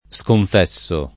sconfessare v.; sconfesso [ S konf $SS o ]